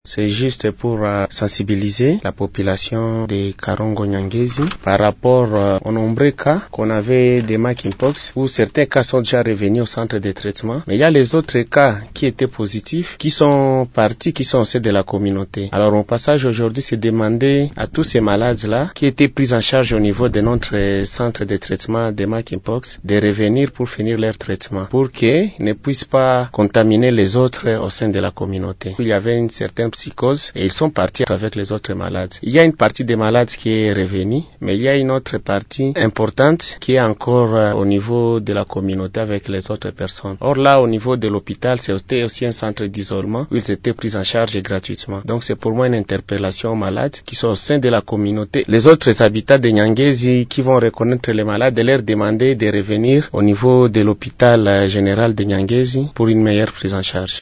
dans un entretien avec Radio Maendeleo